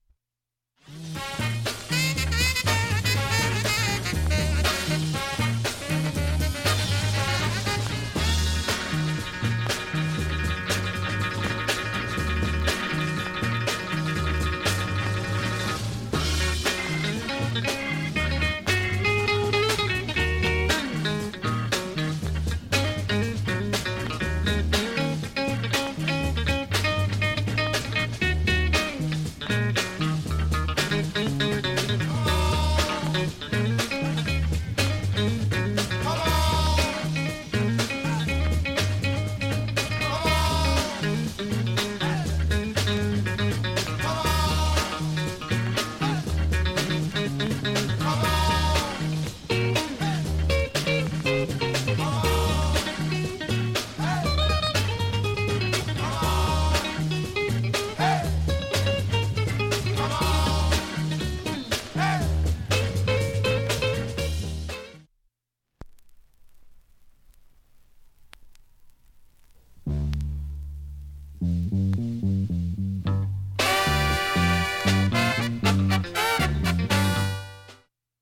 盤面きれいで音質良好全曲試聴済み。
３回までのかすかなプツが６箇所
単発のかすかなプツが１８箇所
インストルメンタルアルバム作品。